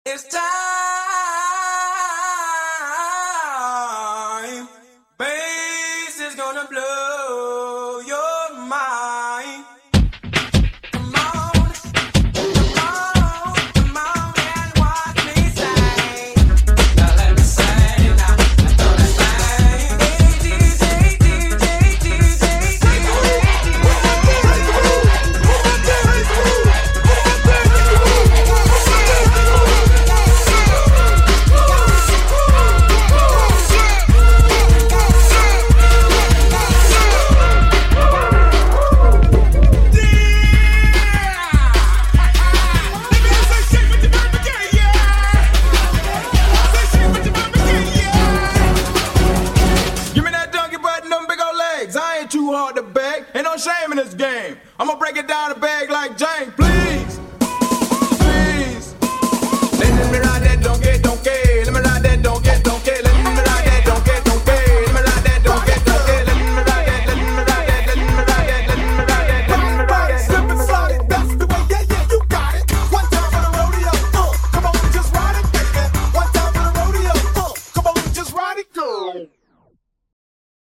Who remembers these Miami bass songs???